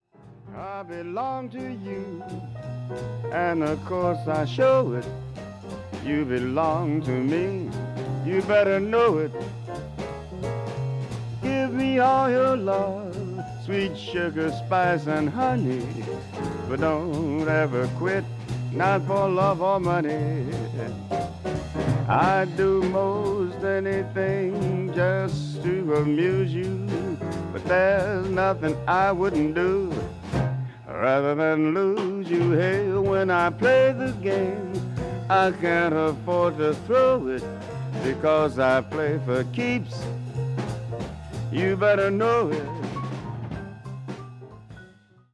ラジオ番組のために録音された音源なのだそう。